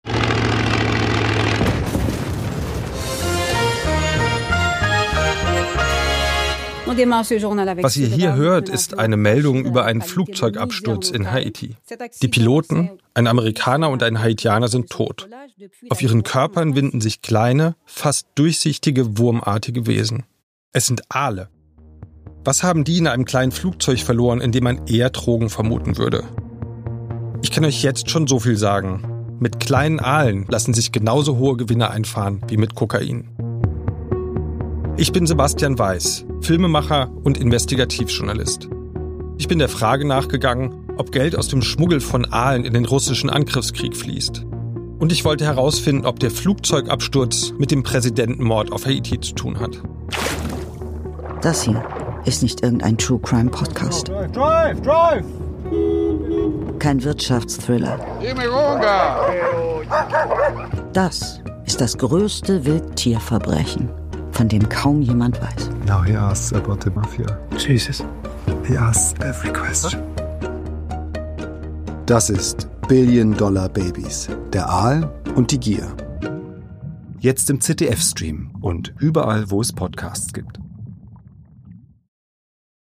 begleitet Katja Riemann als Stimme des Aals diese Reise durch eine